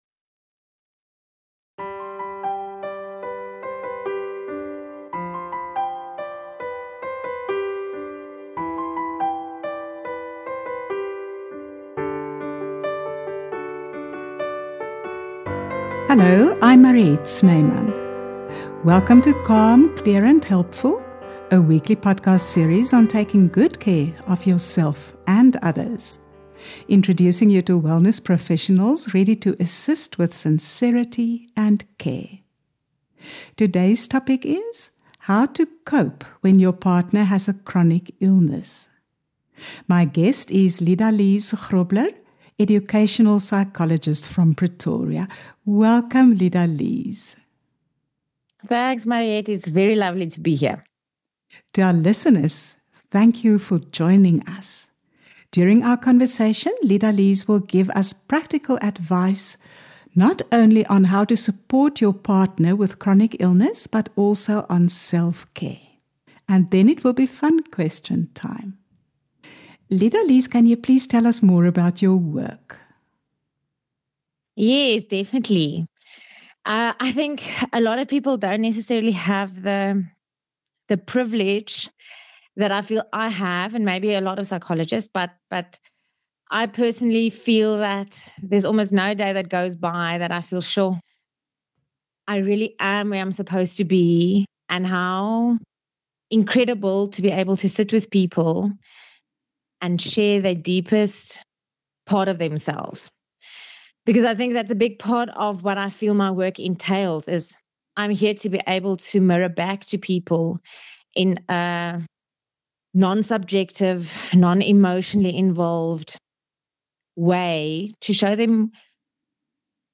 interviews a range of experts on holistic health, love relationships, parenting, and life's phases and challenges.